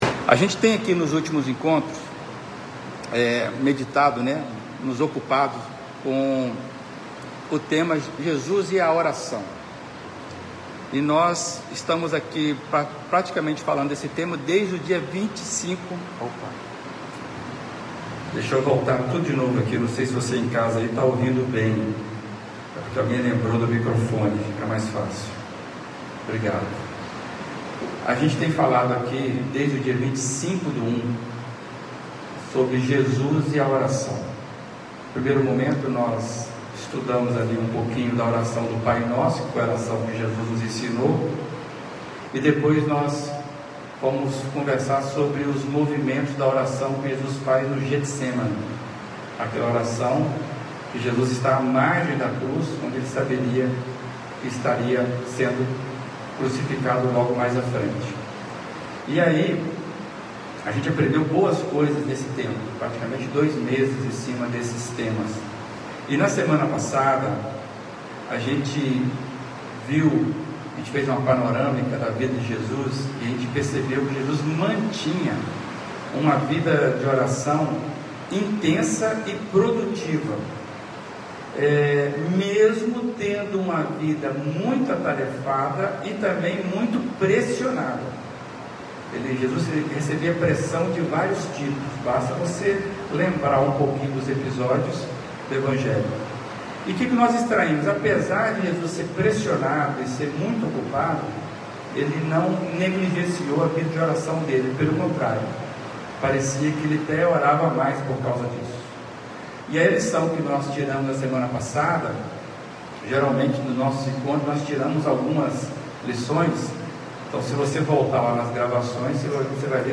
Mensagem apresentada